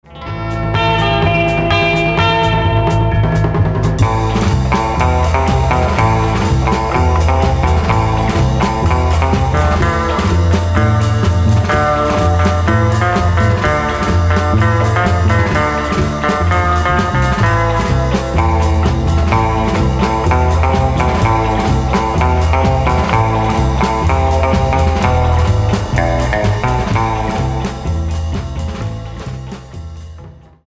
Genre : Rock’ n’ Roll, Instrumental
guitare solo
batterie